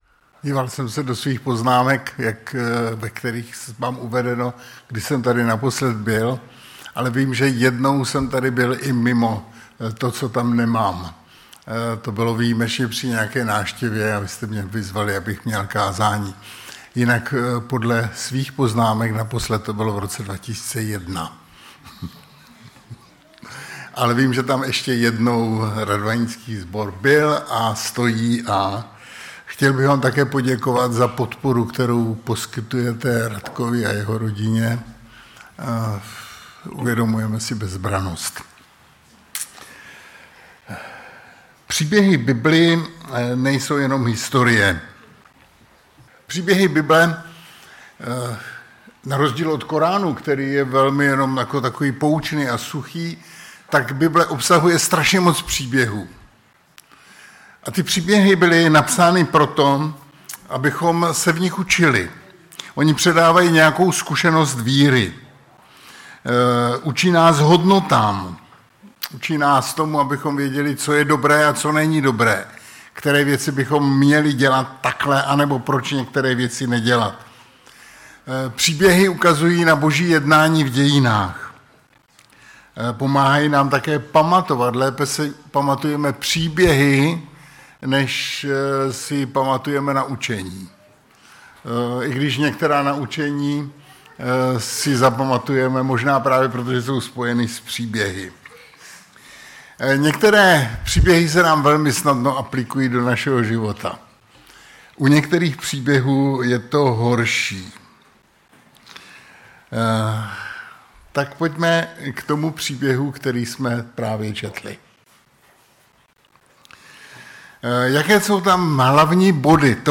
Před kázáním byl čten text z 2. Samuelovy 24,1-13.